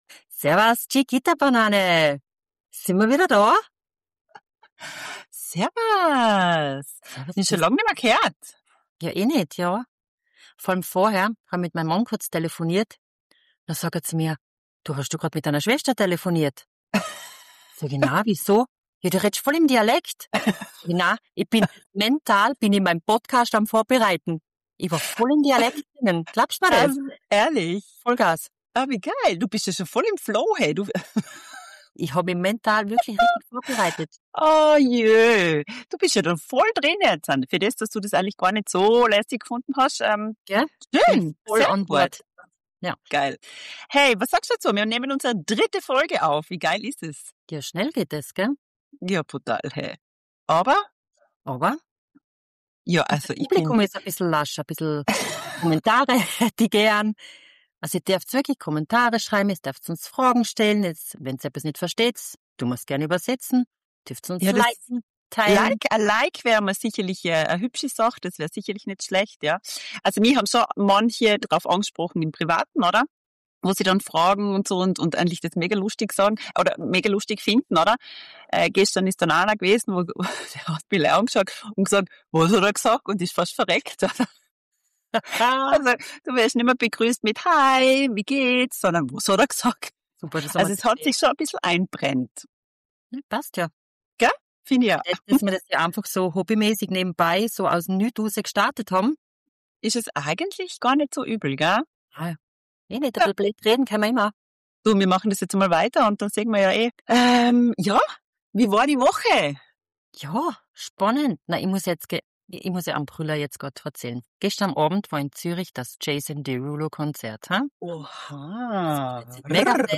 2 Schwestern aus Österreich, zwischen Jason Derulo, Coiffeur Besuch, Hauptgewinn und Dialekt Immer wieder lustige Stories.